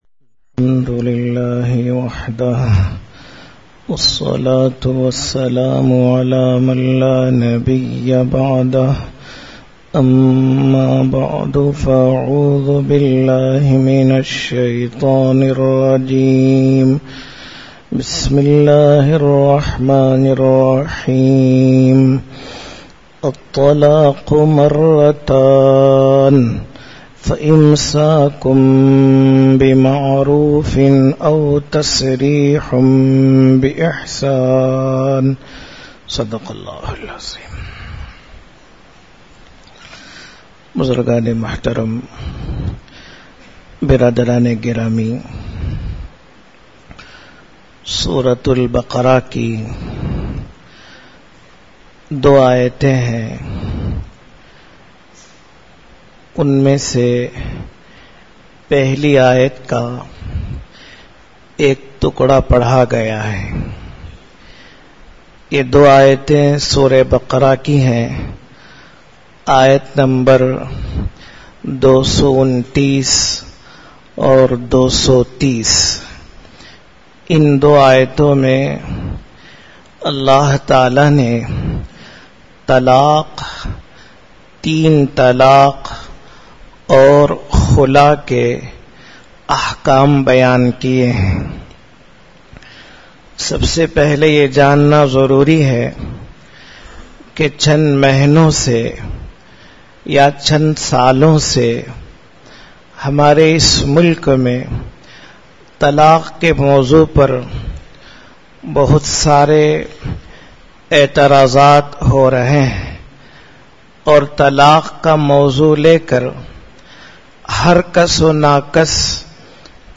Majlis-e-Jamiulkhair, Jamiya Mosque, Ambur.
Bayaan